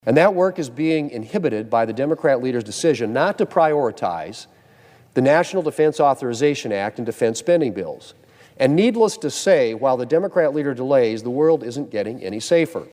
WASHINGTON, D.C.(HubCityRadio)- Earlier this week, Senator John Thune was on the floor of the Senate stressing the importance of getting the National Defense Authorization Act(NDAA) done by the end of this year.